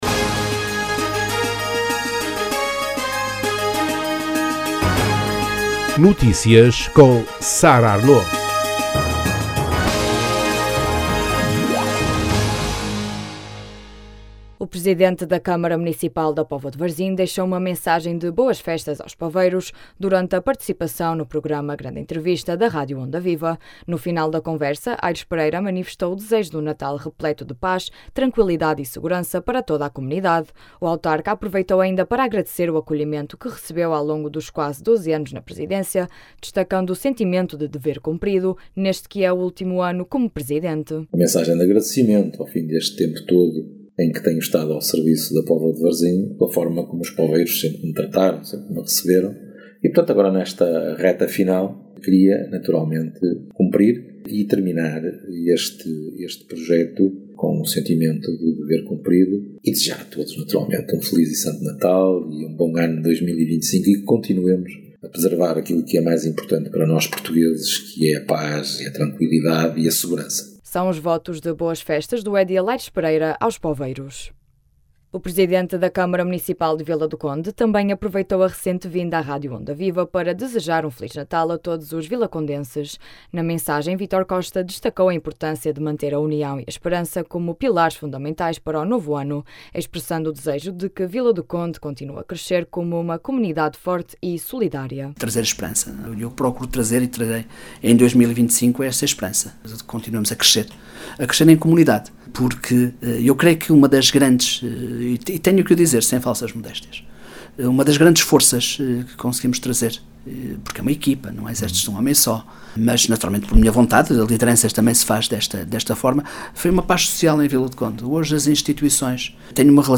Na última edição do programa Grande Entrevista da Rádio Onda Viva, o autarca desejou um Natal marcado pela paz, tolerância e respeito, apelando à atenção para com os outros. Reforçou ainda que a verdadeira felicidade só é alcançada quando quem nos rodeia também está bem.